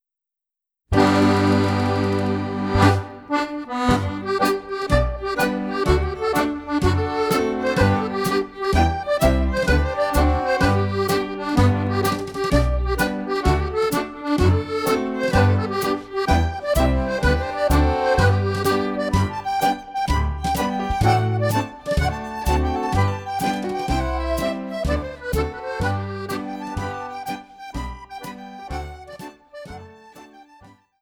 Medley